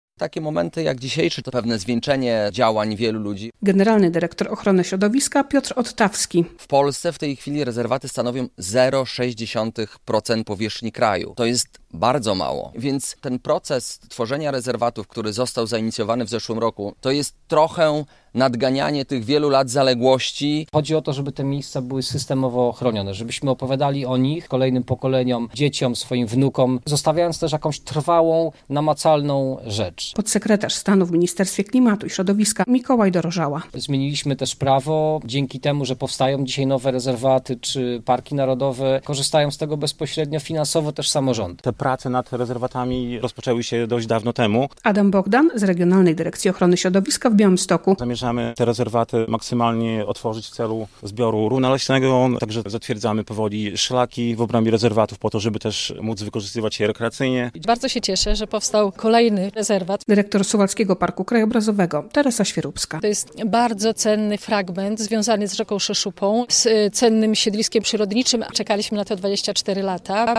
Nowe rezerwaty przyrody - relacja